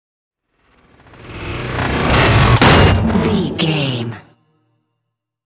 Electronic whoosh to metal hit
Sound Effects
Atonal
dark
futuristic
intense
tension
woosh to hit